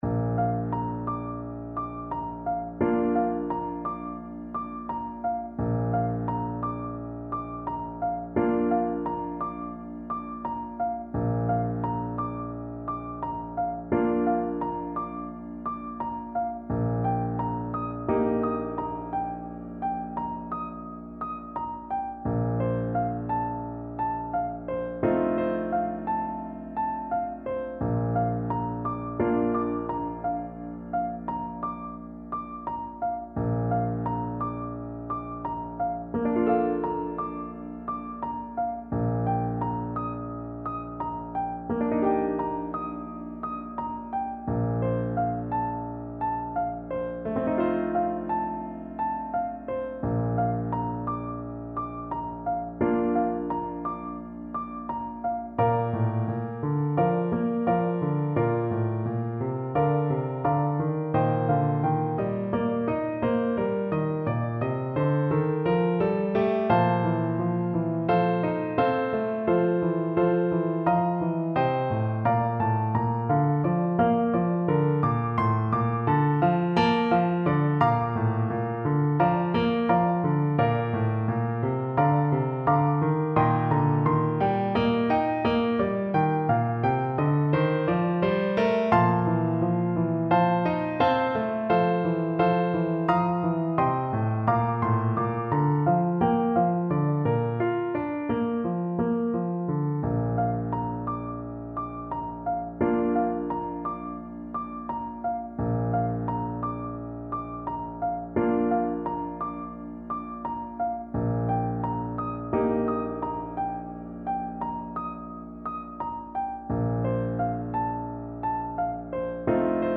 World Africa South Africa Thula Baba Thula Sana (South African trad.)
French Horn
Traditional Music of unknown author.
4/4 (View more 4/4 Music)
Bb major (Sounding Pitch) F major (French Horn in F) (View more Bb major Music for French Horn )
Adagio espressivo =c.60